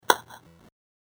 = c09ecbe57f adding sound when you pick up an item. 2014-09-15 22:40:16 -07:00 25 KiB Raw History
pickup.mp3